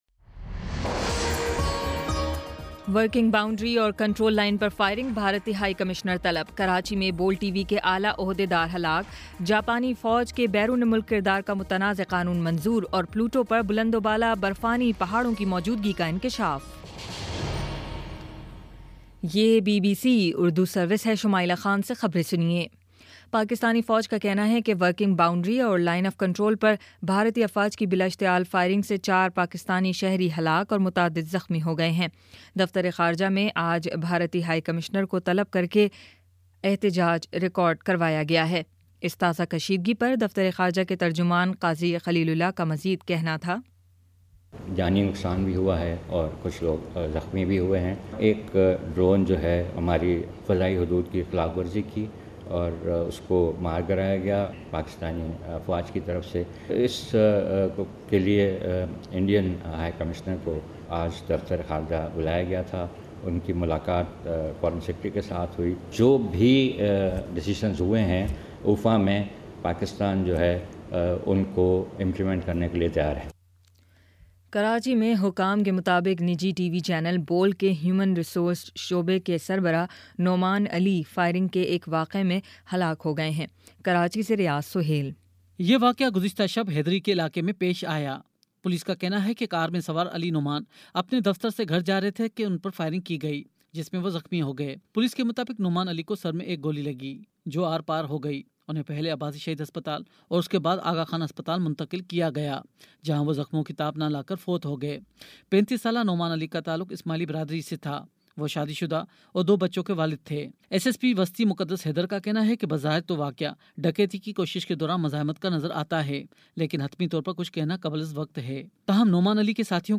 جولائی 16: شام پانچ بجے کا نیوز بُلیٹن